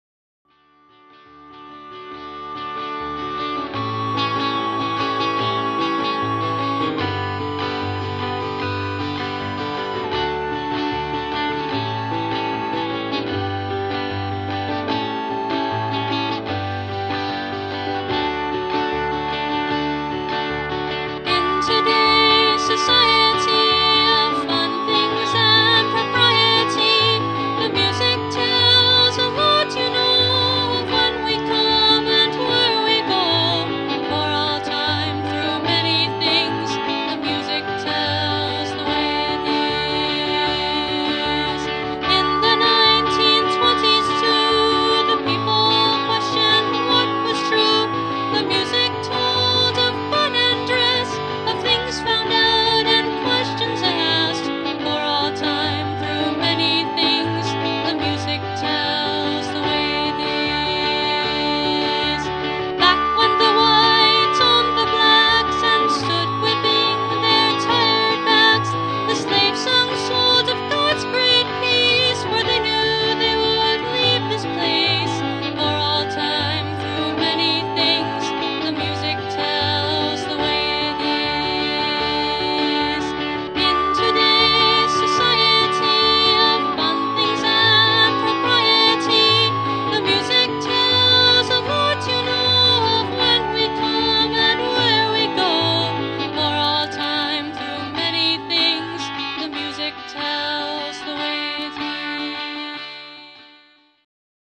Instruments: Acoustic guitar, keyboard, soprano recorder.